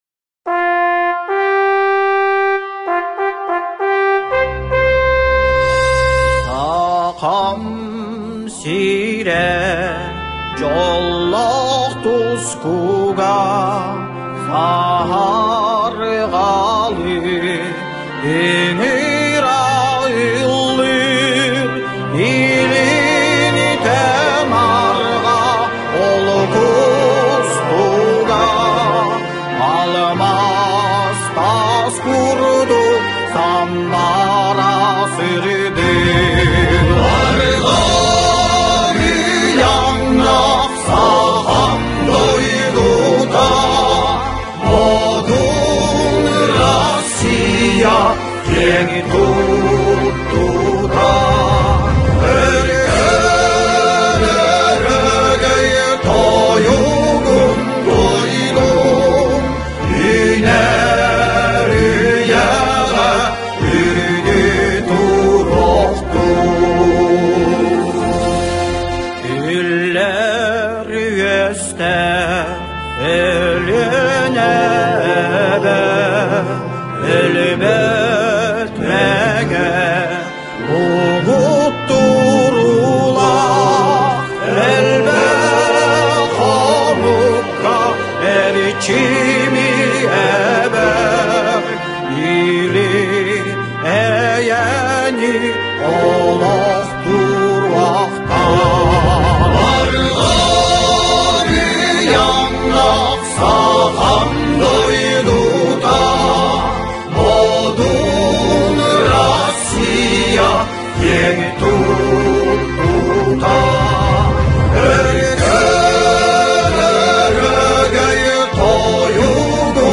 музыка со словами